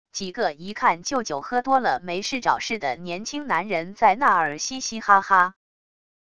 几个一看就酒喝多了没事找事的年轻男人在那儿嘻嘻哈哈wav音频